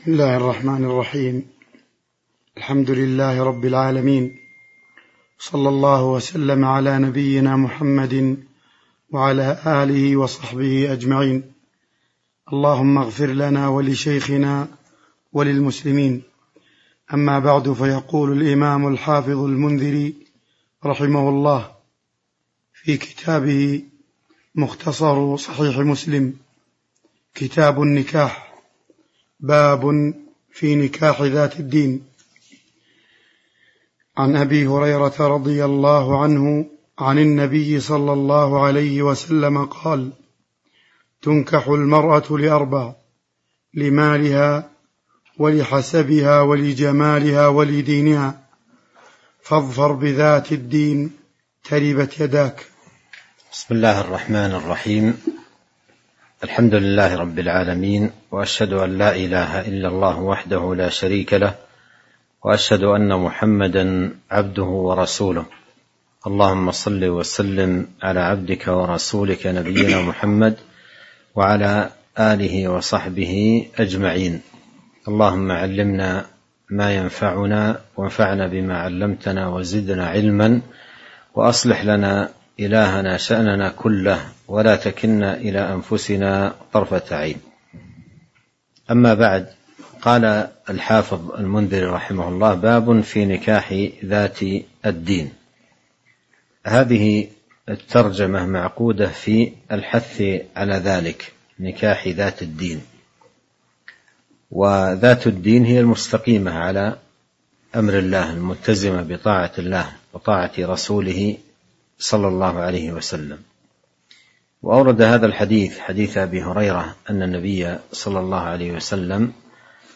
تاريخ النشر ٢٤ ذو الحجة ١٤٤٢ هـ المكان: المسجد النبوي الشيخ